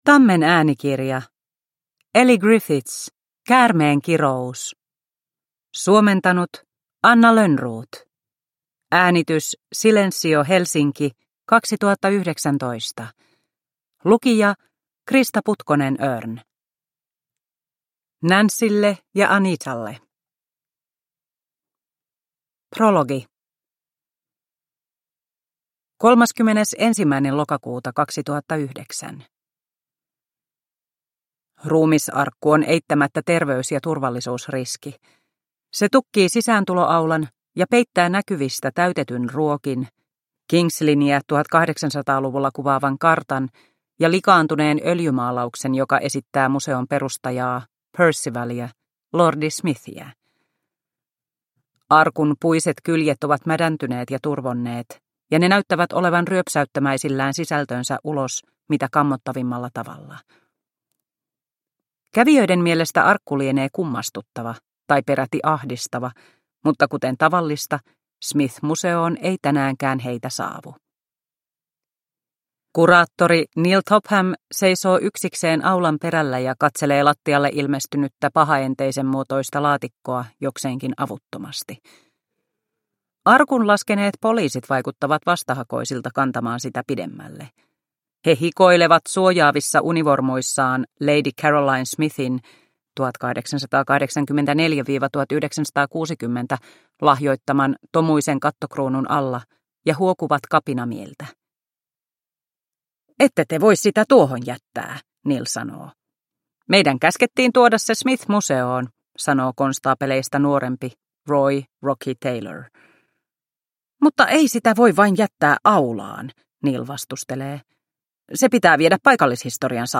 Käärmeen kirous – Ljudbok – Laddas ner